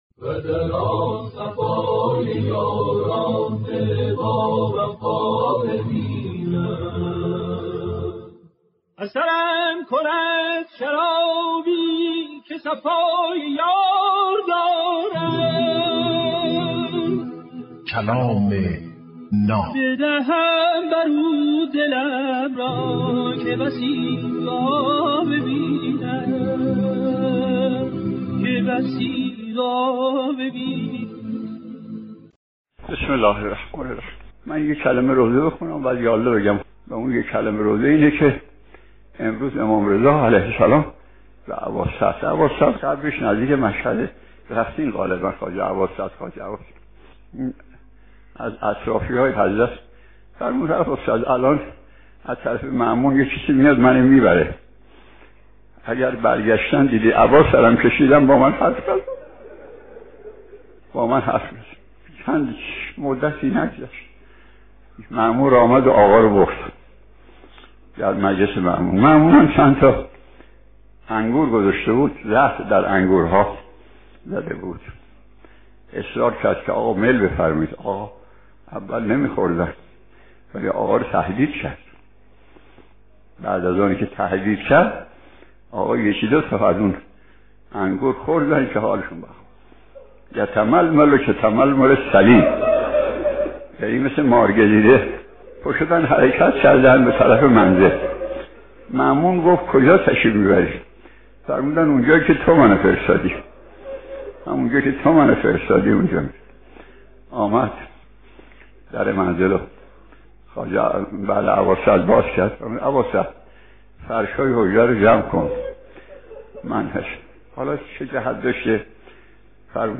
کلام ناب برنامه ای از سخنان بزرگان است که هر روز ساعت 07:10 به وقت افغانستان به مدت 8دقیقه پخش می شود